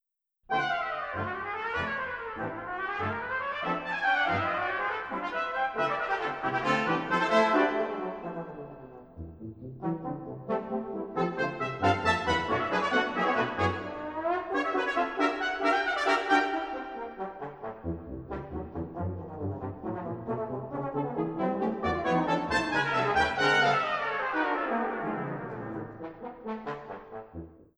The piece is stunningly simplistic in its construction, comprised of just two competing melodies each played twice then repeated in sequence, with each repetition gradually becoming louder and more harmonically complex. The two melodies are accompanied by a relentless bolero rhythm played by a lone snare drum.